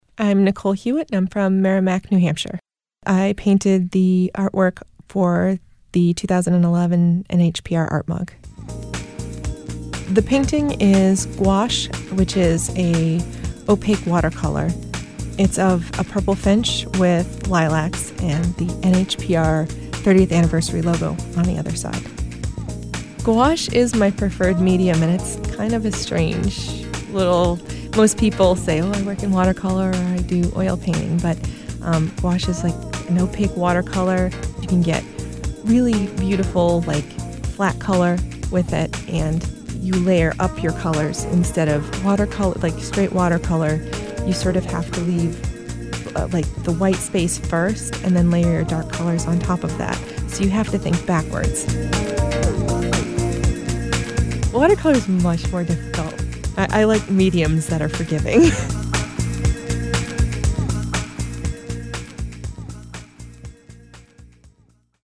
NHPR Interview Clips